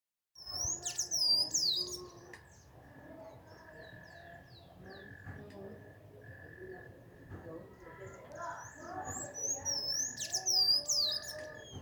Celestino Común (Thraupis sayaca)
Nombre en inglés: Sayaca Tanager
Localidad o área protegida: Concordia
Condición: Silvestre
Certeza: Observada, Vocalización Grabada